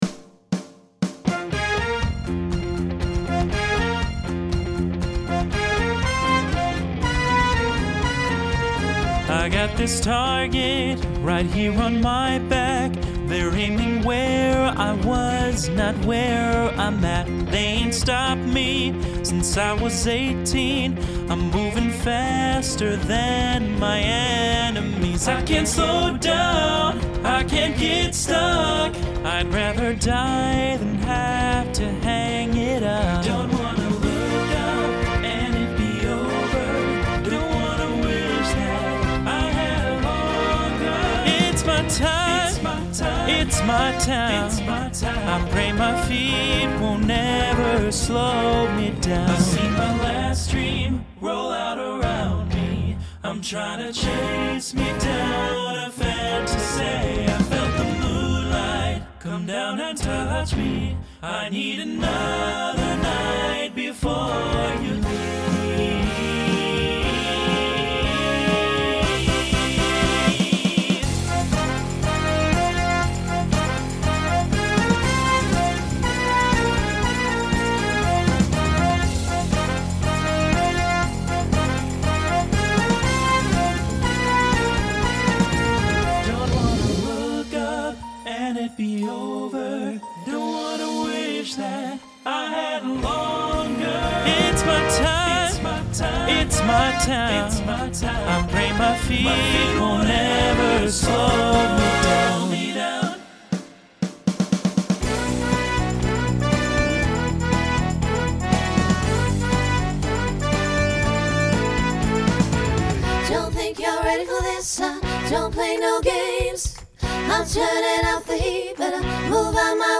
ends with an SATB overlap.
Genre Rock
Transition Voicing Mixed